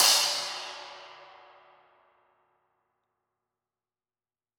Index of /musicradar/Cymbals/Trash Crashes
CYCdh_TrashE-04.wav